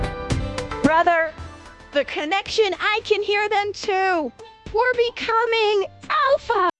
novafarma/assets/audio 🔴/voiceover/prologue/prologue_11.wav